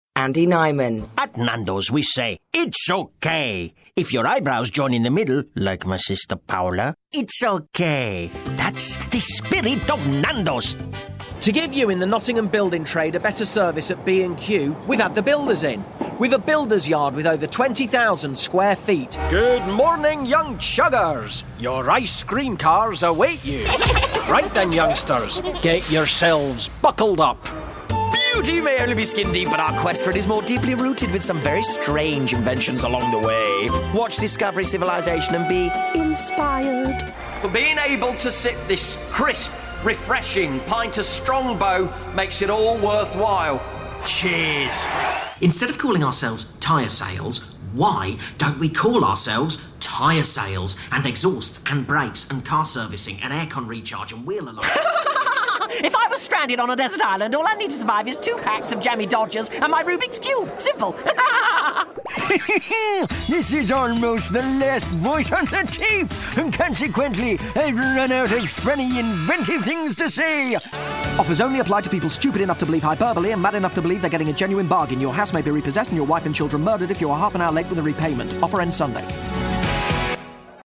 Voice Reel
Andy Nyman - Character Reel
Andy Nyman - Character reel.mp3